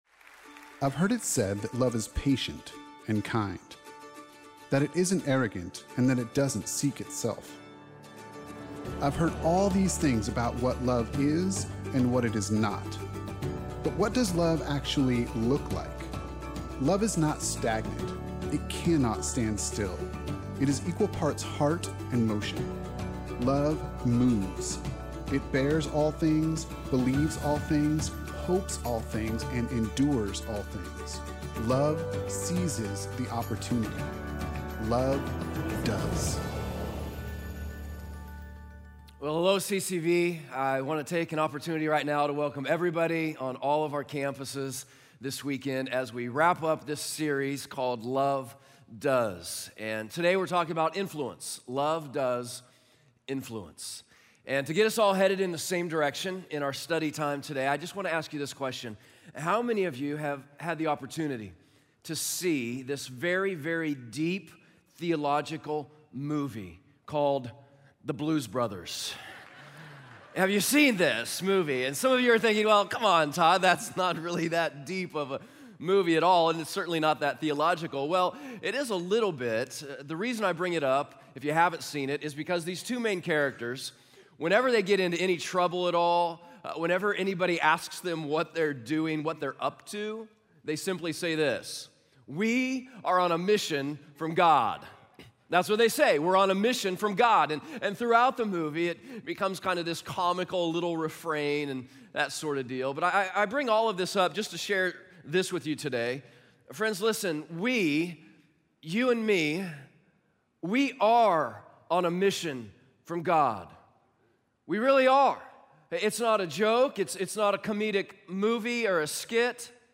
Love Does: Influence (Full Service)